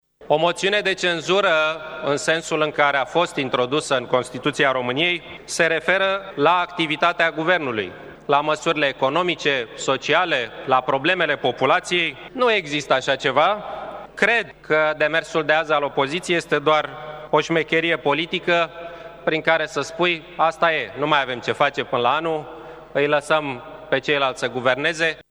Așa a declarat, azi, în plenul Parlamentului, premierul Victor Ponta.